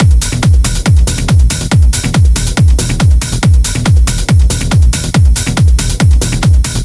我使用了一些BuzzGenerator声音和一些Porta效果来制造一个类似声音的声音。
标签： 曲目 剪辑 总之 140BPM TECHNO 精神恍惚
声道立体声